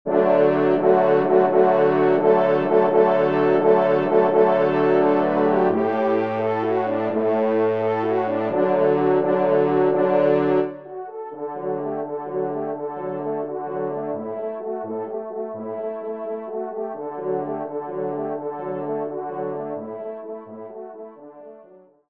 TROMPE 3 en Exergue